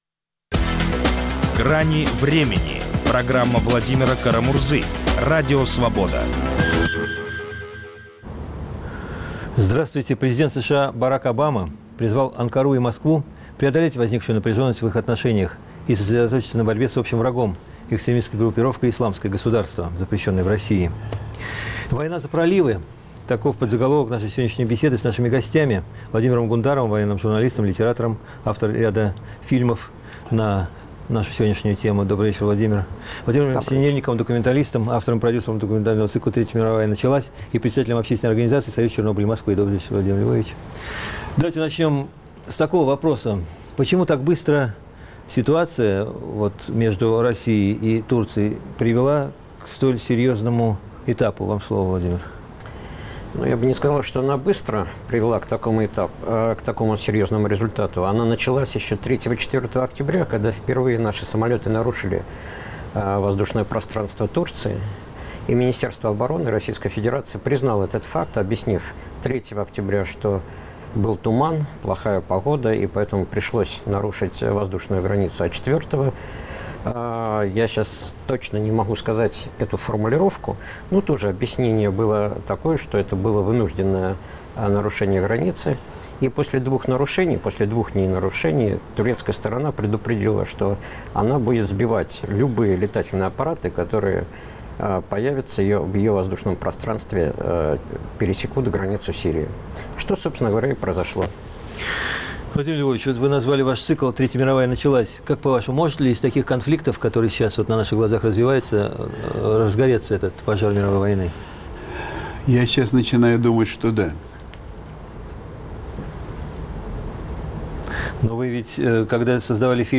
спорят адвокат, специалист по морскому праву